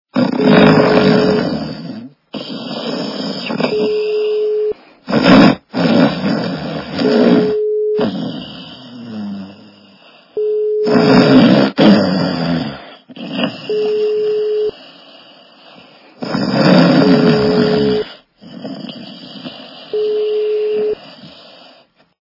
При прослушивании Мужской - храп качество понижено и присутствуют гудки.
Звук Мужской - храп